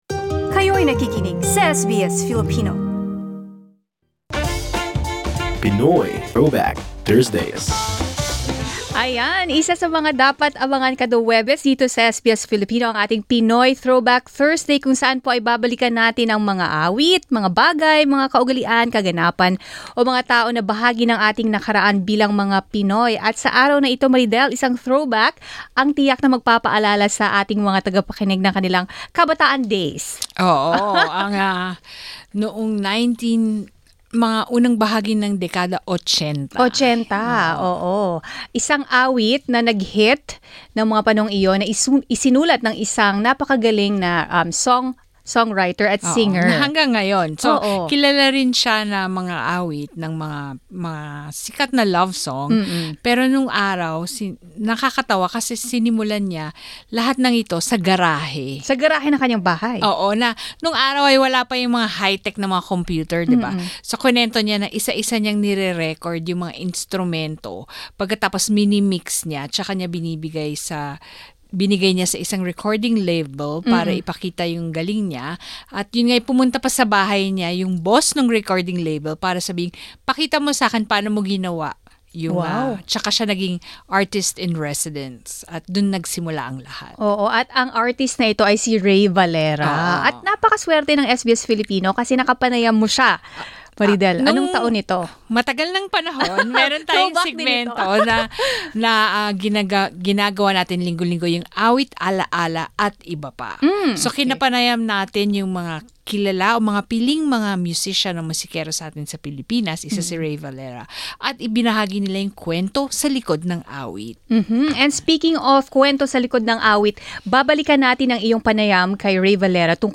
Taong 1978 nang sumikat ang awiting 'Mr. DJ' ni Sharon Cuneta na isinulat ni Rey Valera. Ating balikan ang kwento sa likod ng sikat na awitin mula sa panayam ng SBS Filipino kay Rey Valera.